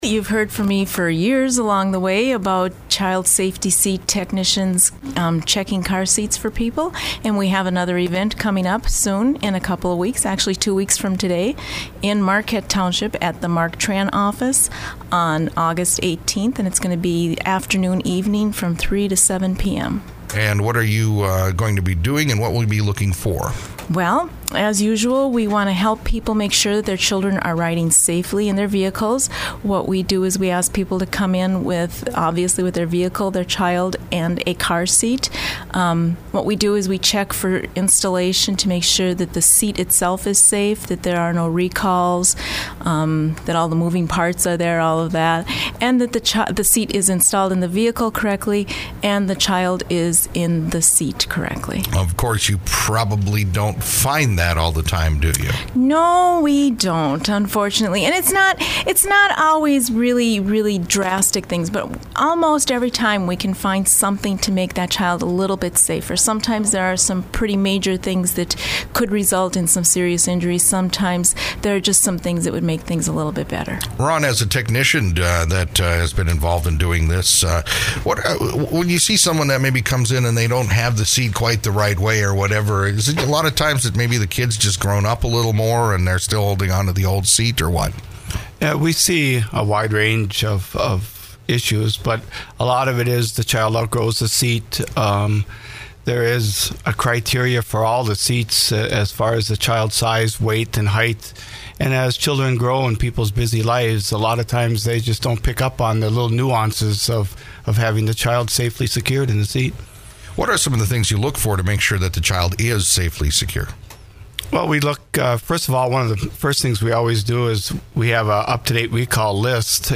Old Interviews Archive